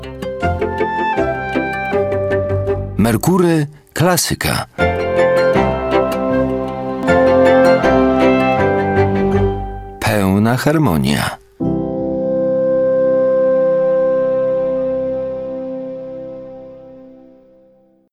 Male 30-50 lat
A voice with a low, sandy timbre, constantly seeking new challenges.
Zapowiedź radiowa